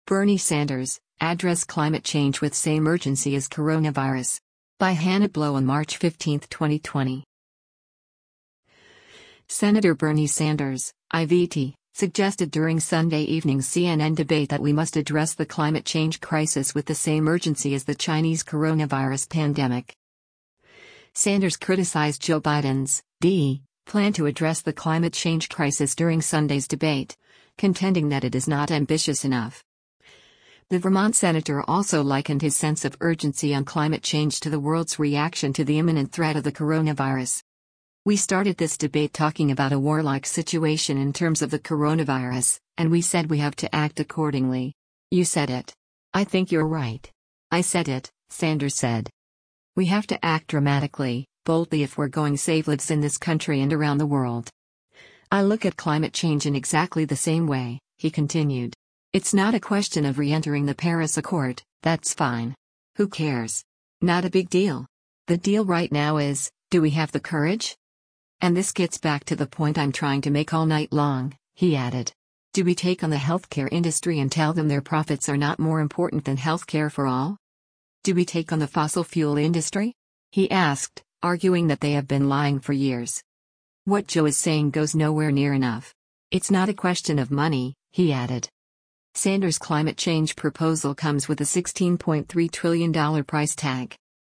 Sen. Bernie Sanders (I-VT) suggested during Sunday evening’s CNN debate that we must address the climate change “crisis” with the same urgency as the Chinese coronavirus pandemic.